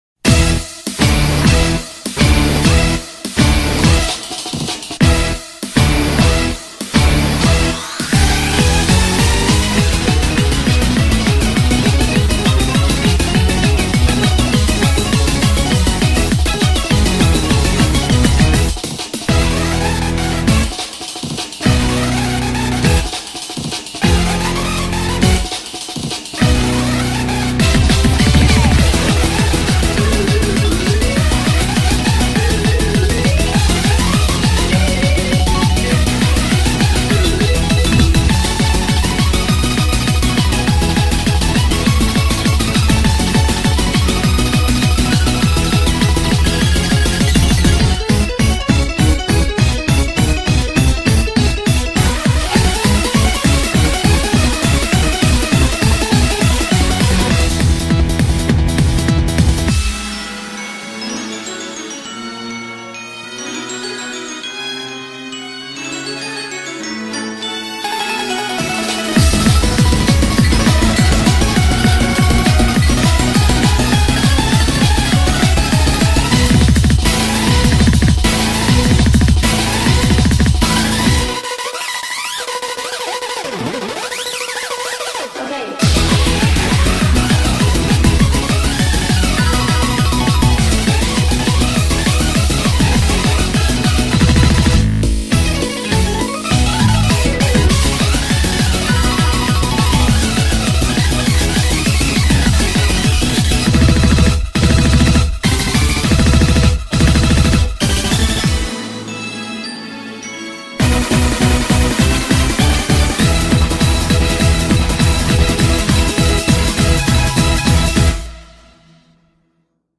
BPM202
MP3 QualityLine Out
instrumental